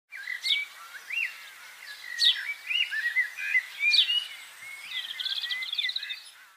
Ptarmigan Chirping Sound Effects Free Download